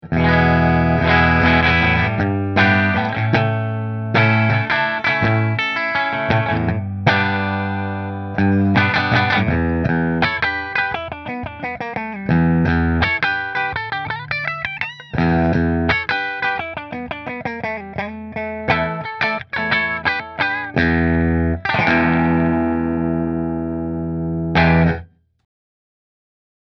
It comes with a mahogany neck through neck, 2 custom wound humbuckers with coil splitting, and a beautiful dark green flame top.
New Orleans Guitars Voodoo Custom Dark Green Bridge Through Fender